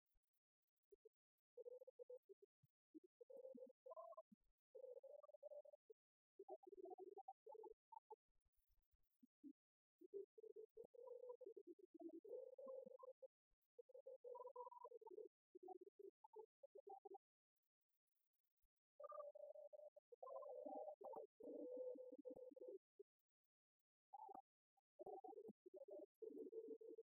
Informateur(s) Club des retraités de Beaupréau association
Chansons du Club des retraités
Pièce musicale inédite